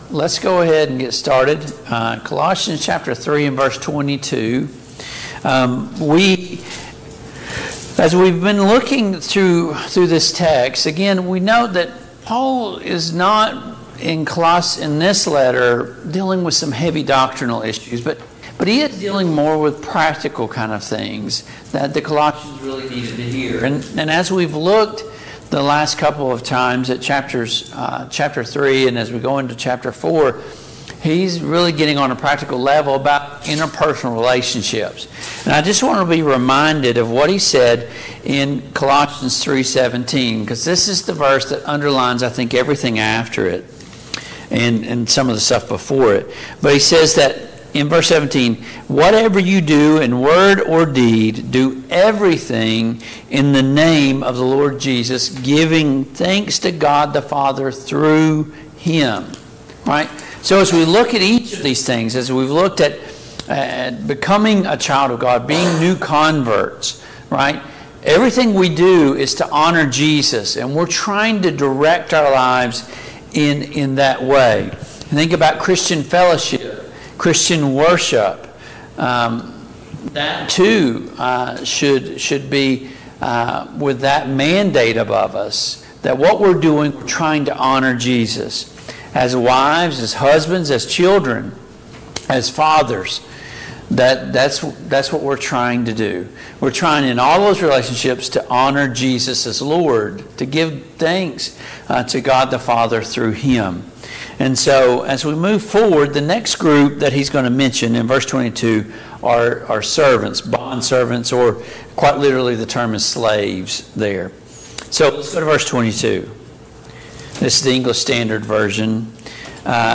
Passage: Colossians 4:1-17 Service Type: Mid-Week Bible Study Download Files Notes « 2.